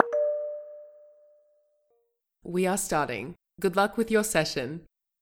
focus_alert.wav